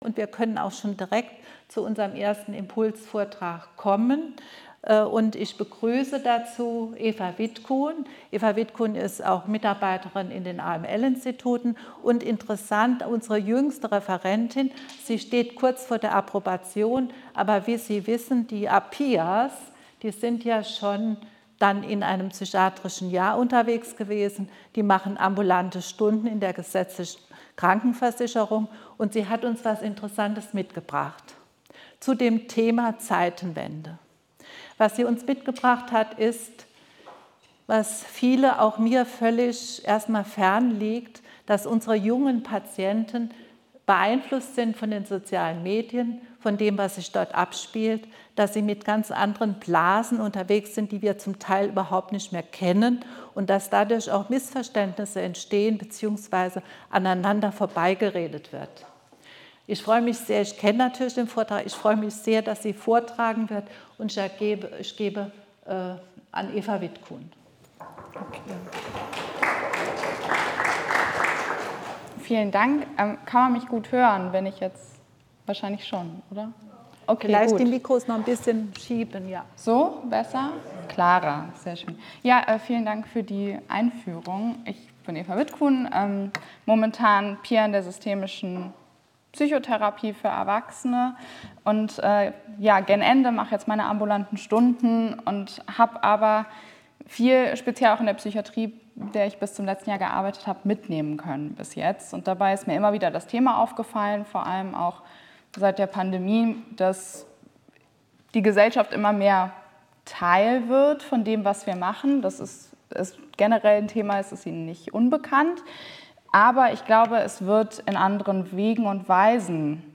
Vortrag 6.5.2023, Siegburg: 28. Rheinische Allgemeine PSYCHOtherapietage - Psychotherapie nach der Zeitenwende